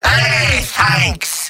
Robot-filtered lines from MvM. This is an audio clip from the game Team Fortress 2 .
{{AudioTF2}} Category:Demoman Robot audio responses You cannot overwrite this file.